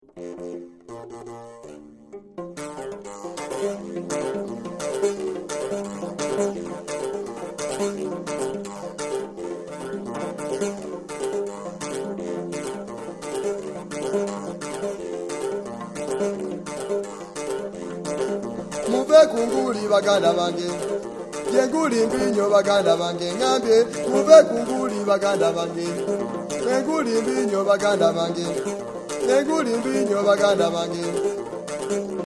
female voice, yodeling, ullulation, handclapping (engalo)
ebinyege (rattles), engalo (handlapping)